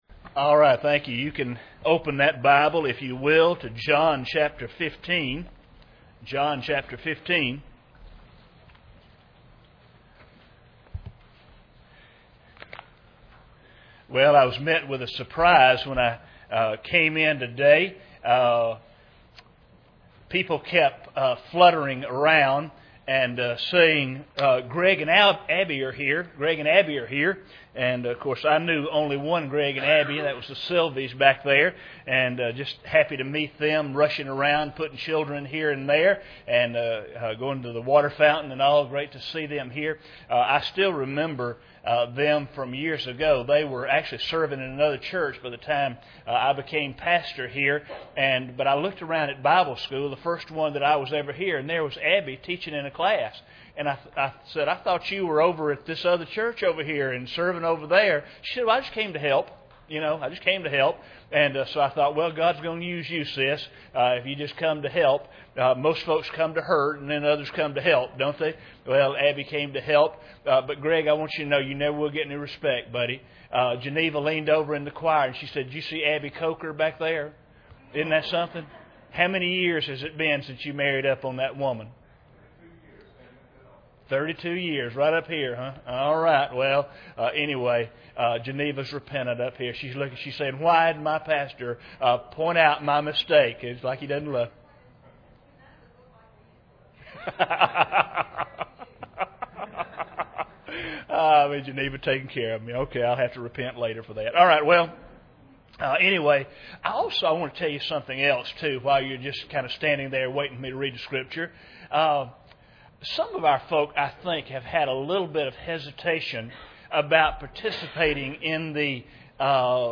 John 15:1-27 Service Type: Sunday Morning Bible Text